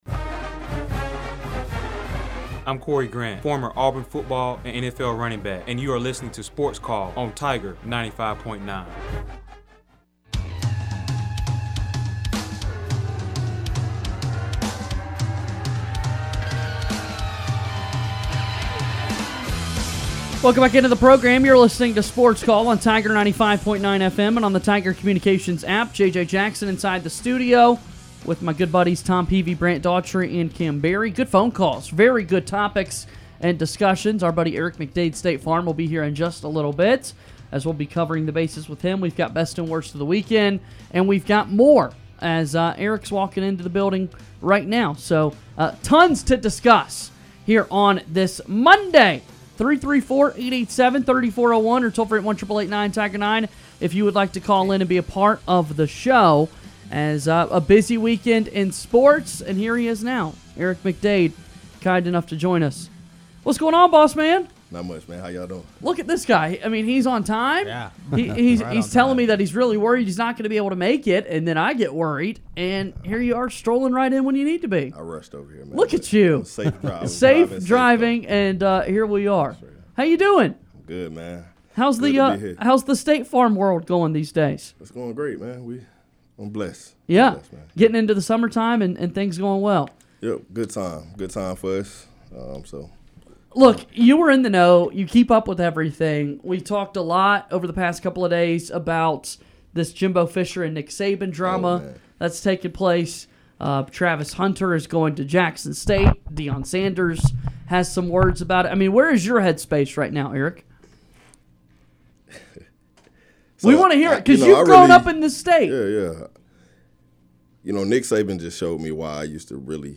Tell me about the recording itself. into our SportsCall + Tiger 95.9 studio to discuss the NBA Conference Finals, the drama between Nick Saban and Jimbo Fisher, and where the Lakers will turn for a new head coach.